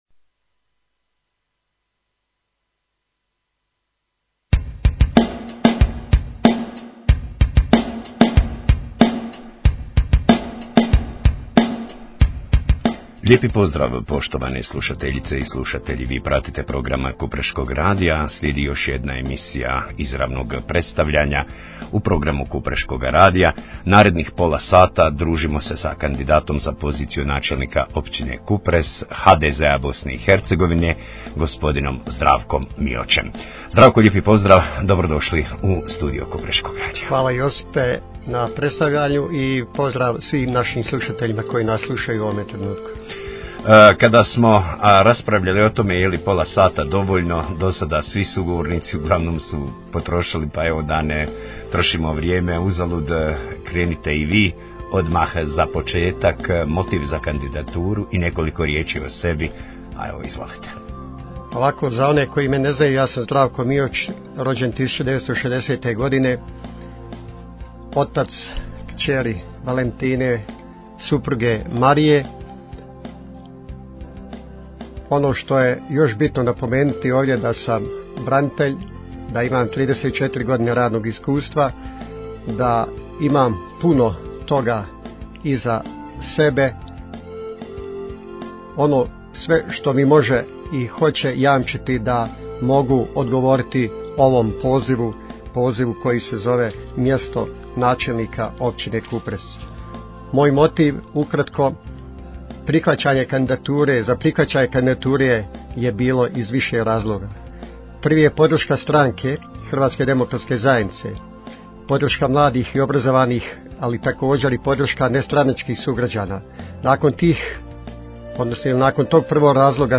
Izravno obraćanje kandidata za poziciju načelnika Općine Kupres